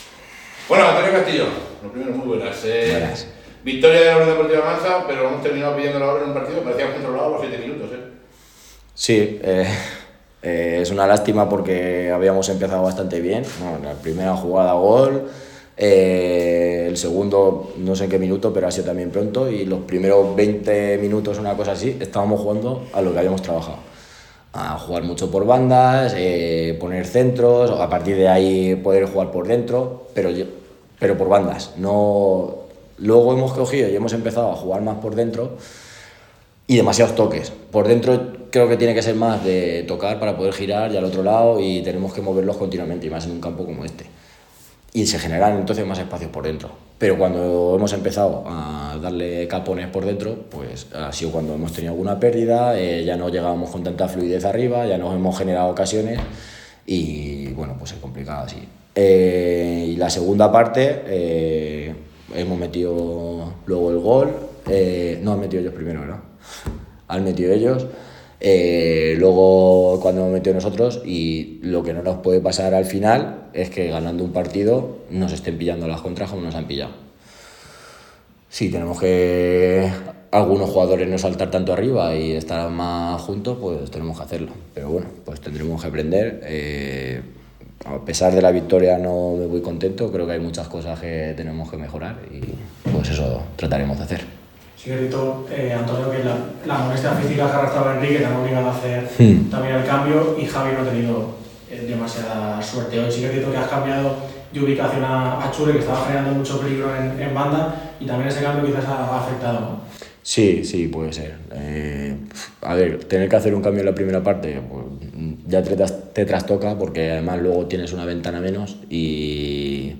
Rueda-de-Prensa-Teresiano.mp3